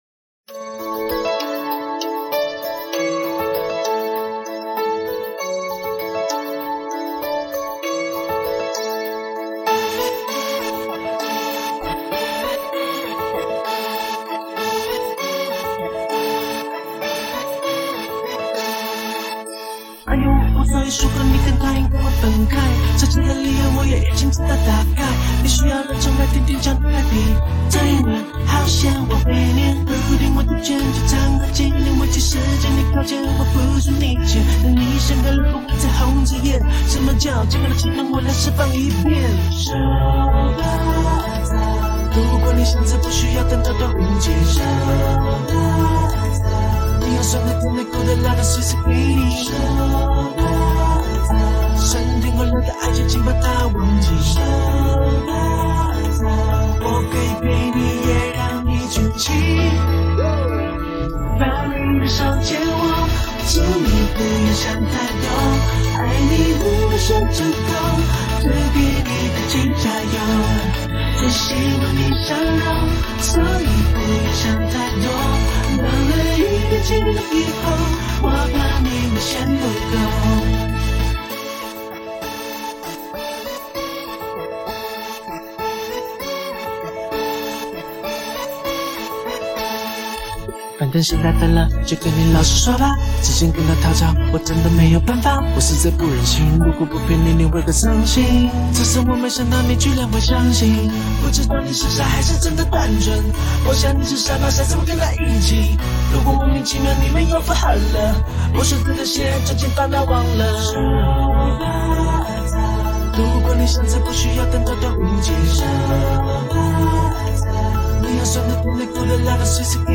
华语